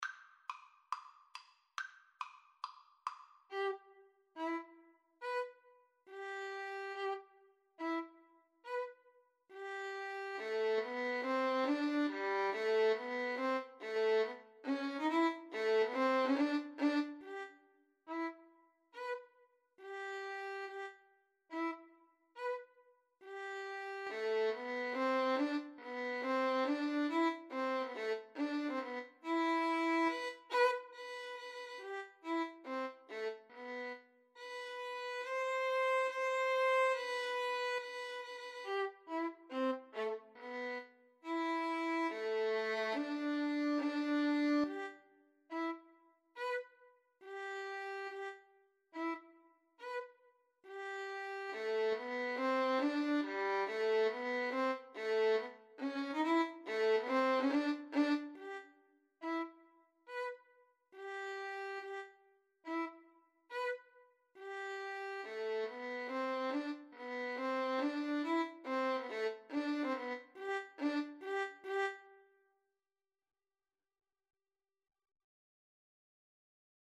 4/4 (View more 4/4 Music)
Tempo di marcia =140
Classical (View more Classical Violin Duet Music)